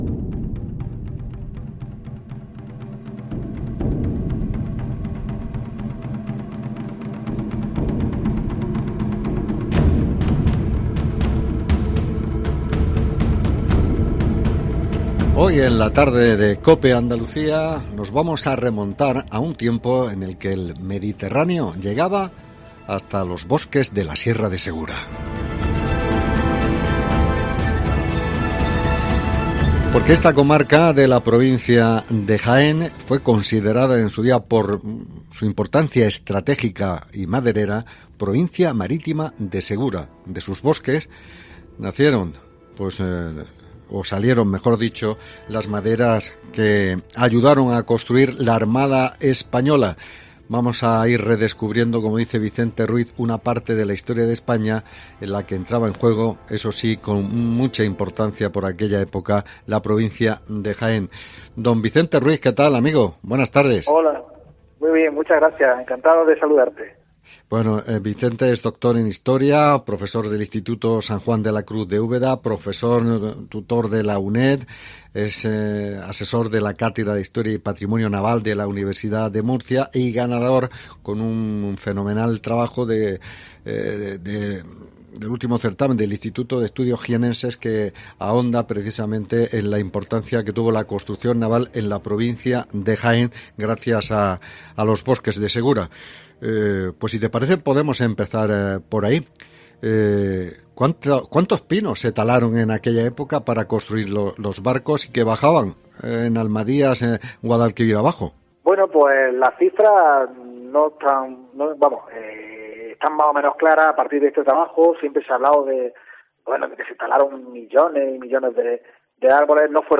Preguntas y respuestas